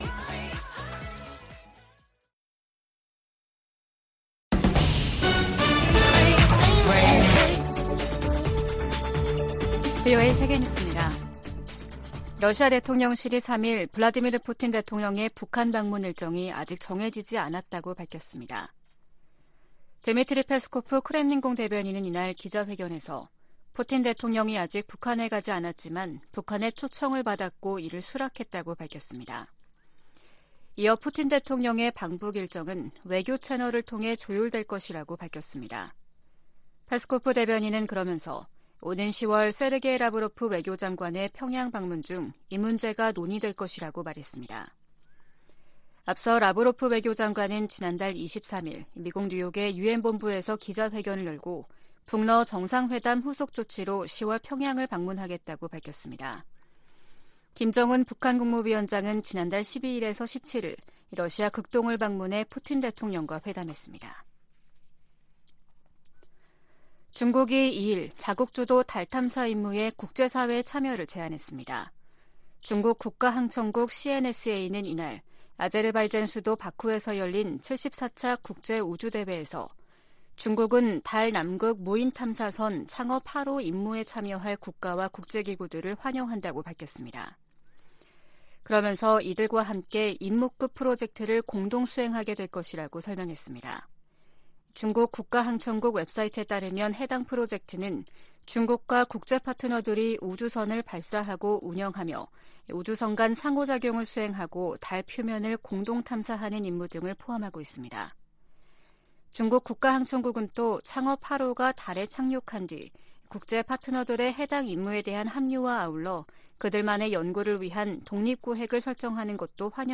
VOA 한국어 아침 뉴스 프로그램 '워싱턴 뉴스 광장' 2023년 10월 4일 방송입니다. 미 국무부는 중국이 대북 영향력을 활용해 북한을 외교로 복귀시켜야 한다고 밝혔습니다. 유엔총회 제1위원회 회의에서 미국은 북한과 러시아 간 무기 거래가 국제 평화에 대한 중대한 위협이라고 지적했습니다. 북한이 군사정찰위성을 세 번째로 쏘겠다고 공언한 10월에 접어들면서 관련국들이 북러 군사 협력 가시화에 촉각을 곤두세우고 있습니다.